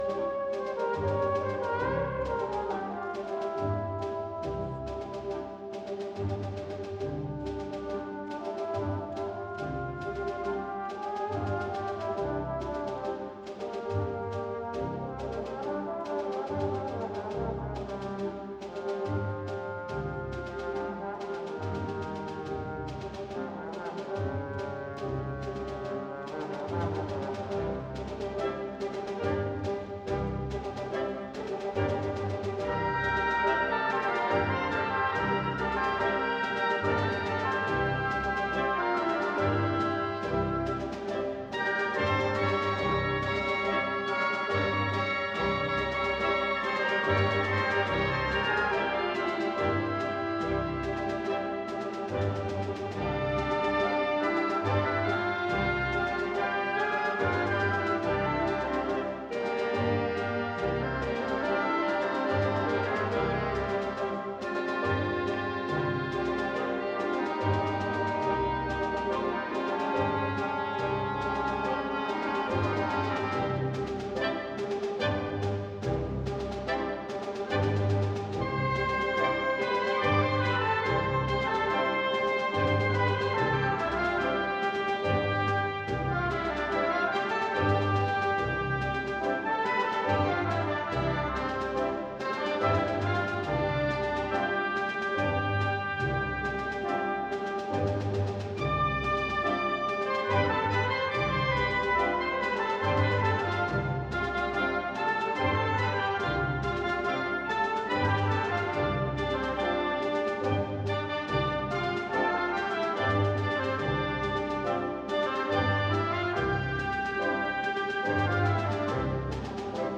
Partitions pour orchestre d'harmonie.
• View File Orchestre d'Harmonie